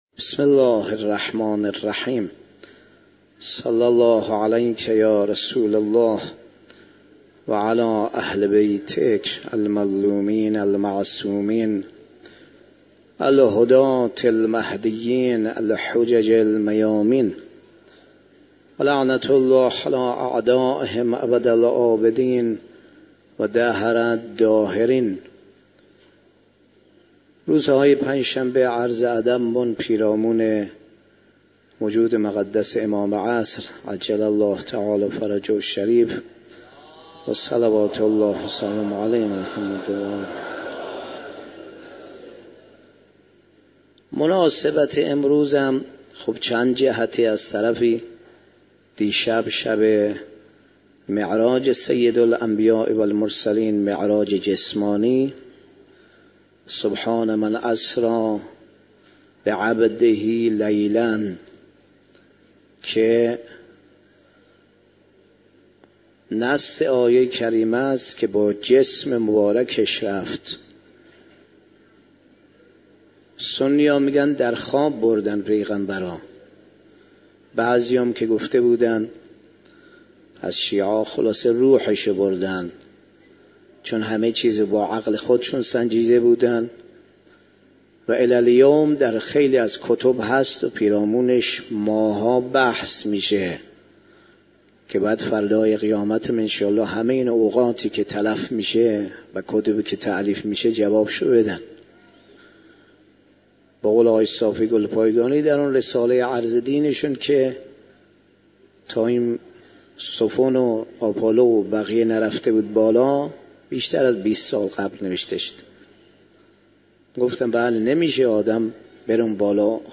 شب هفدهم رمضان 96 - حسینیه امام منتظر - سخنرانی